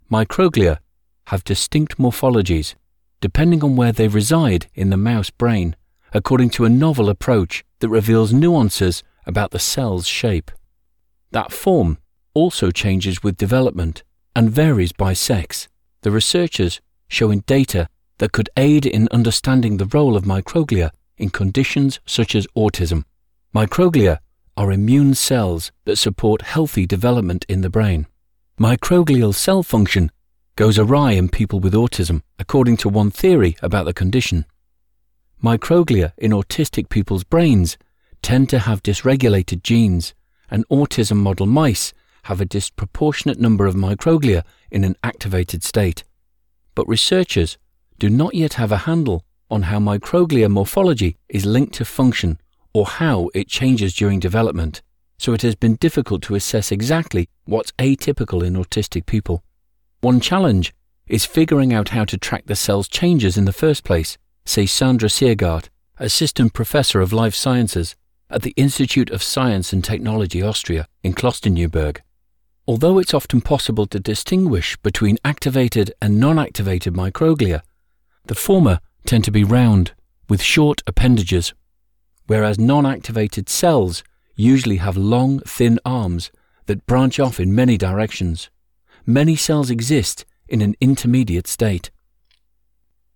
Male
English (British)
My voice is mature and deep with an authoritative, conversational style. Other characteristics of my voice are believable, articulate and sincere.
Microglia Medical Narration
0513Microglia_Demo.mp3